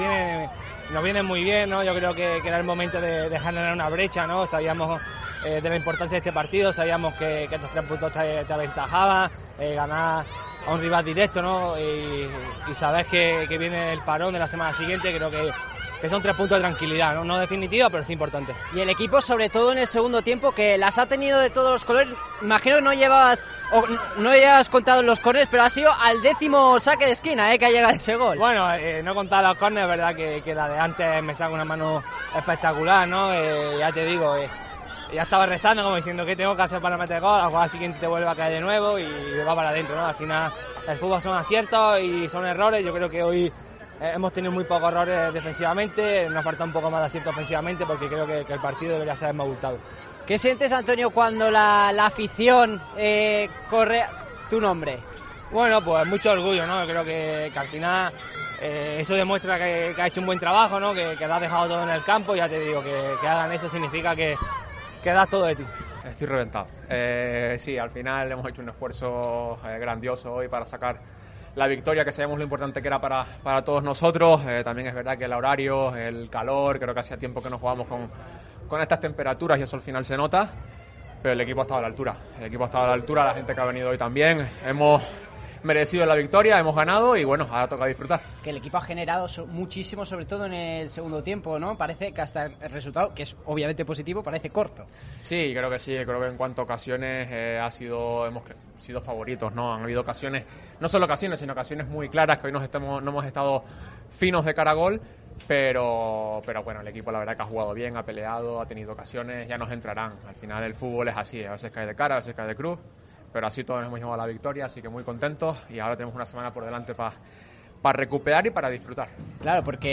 Declaraciones a los medios del club de Raíllo, Mascarell, Antonio y Aguirre.